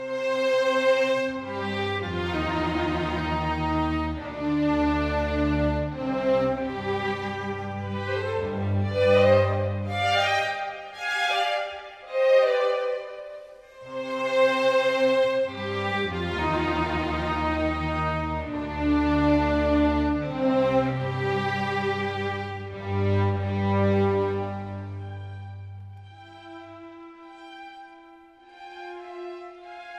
plays with a rich warmth and dark, richly burnished tone
Adagio sostenuto 10.21